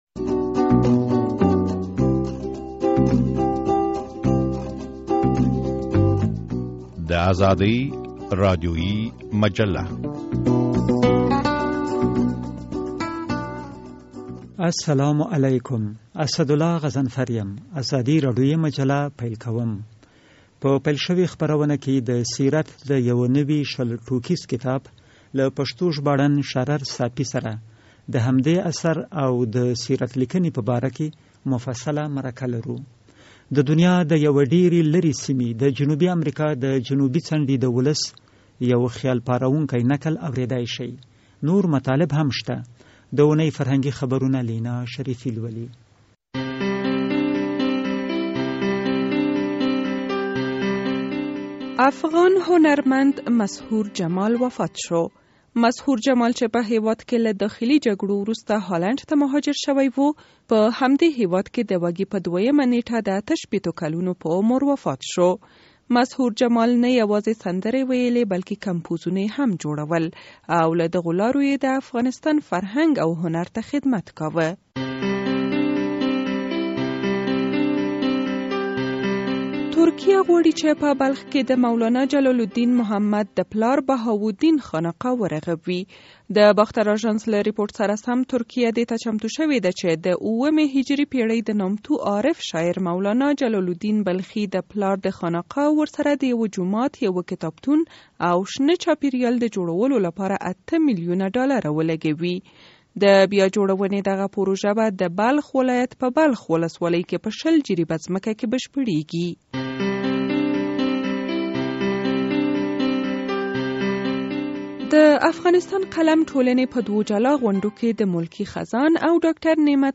د سیرت لیکنې په باره کې مرکه واورئ